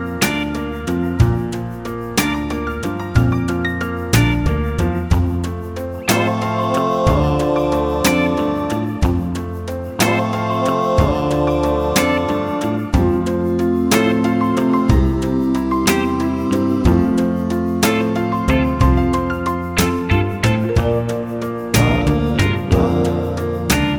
no Backing Vocals Country (Female) 2:59 Buy £1.50